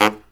LOHITSAX11-L.wav